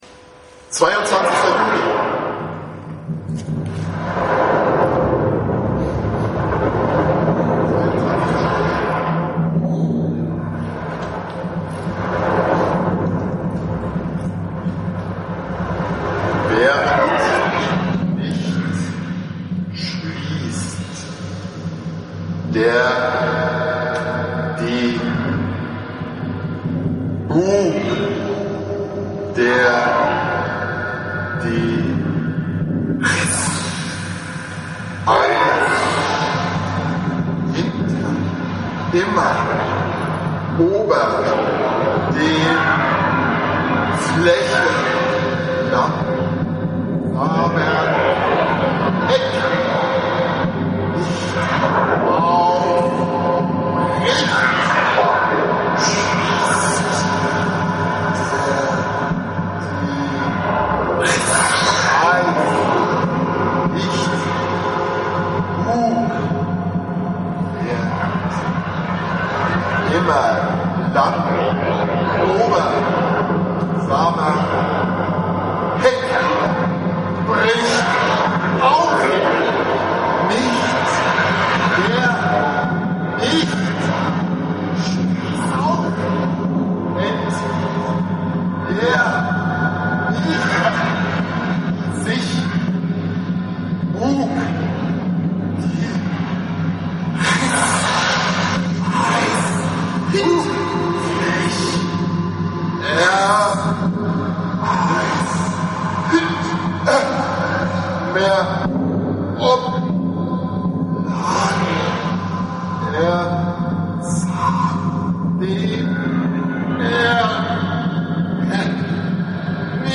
Live Auftritt in der Galerie 'Aujour d'hui', Berlin Mitte am Mundwerk
Live Hörspiel